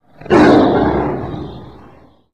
lion2.mp3